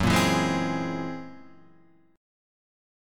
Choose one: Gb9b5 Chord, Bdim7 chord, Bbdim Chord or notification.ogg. Gb9b5 Chord